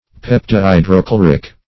Search Result for " peptohydrochloric" : The Collaborative International Dictionary of English v.0.48: Peptohydrochloric \Pep`to*hy`dro*chlo"ric\, a. [See Peptone , and Hydrochloric .]
peptohydrochloric.mp3